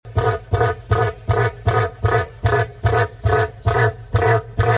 Cassowaries emit vibratory sounds and sound effects free download
Cassowaries emit vibratory sounds and some biologists suggest that the casque on their head amplifies very low-frequency sounds, which may aid in communication in dense rainforests.